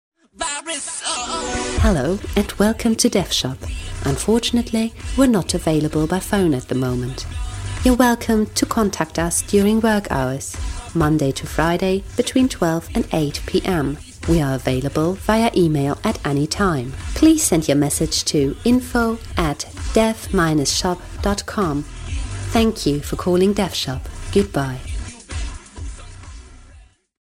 Werbung Rexona Maximum Protection Deo-Spray